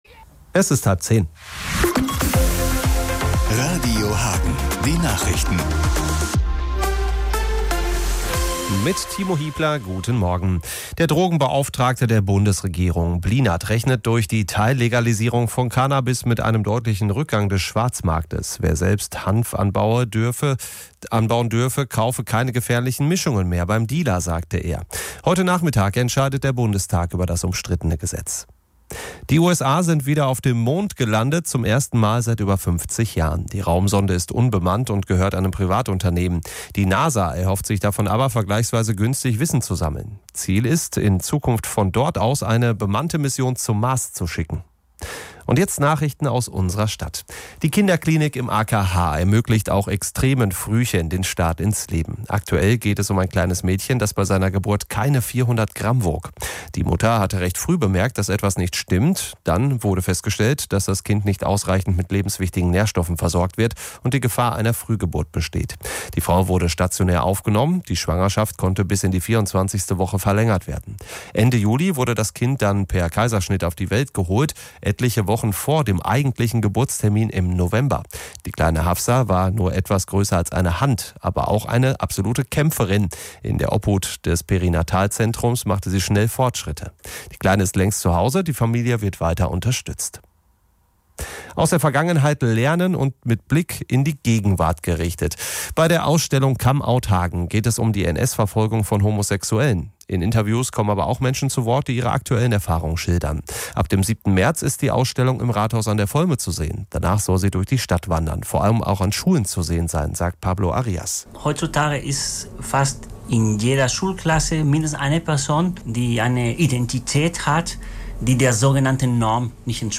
Bei der Ausstellung "Come out, Hagen" geht es um die NS-Verfolgung von Homosexuellen. In Interviews kommen aber auch Menschen zu Wort, die ihre aktuellen Erfahrungen schildern.